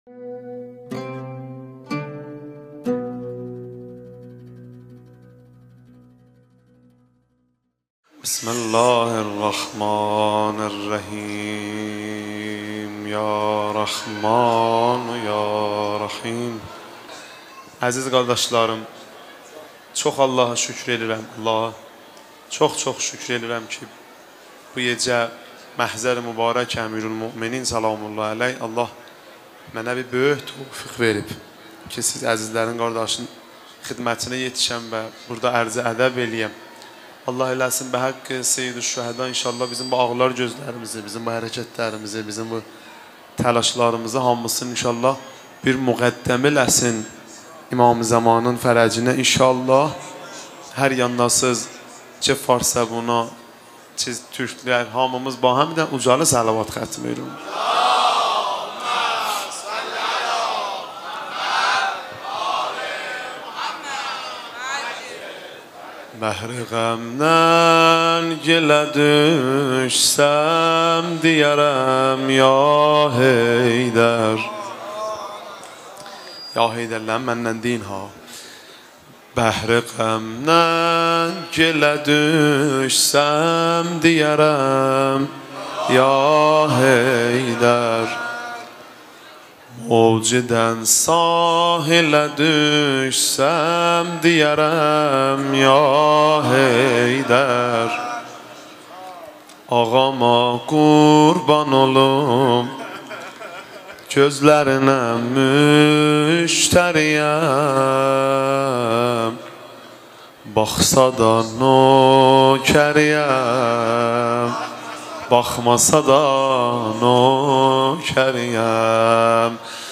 روضه خوانی
اجتماع زائران آذری زبان